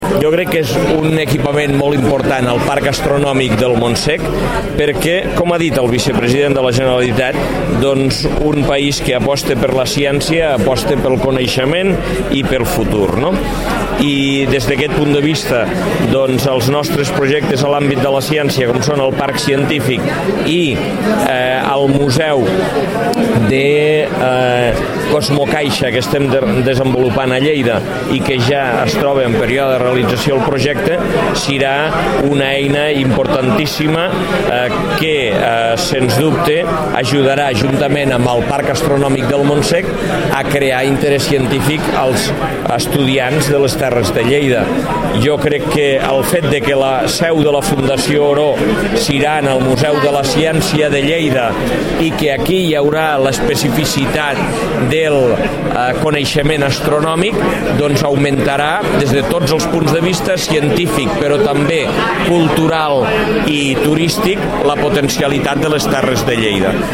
Talls de veu
Consulta els talls de veu (en format MP3) de l'Alcalde i dels diferents regidors de l'Ajuntament de Lleida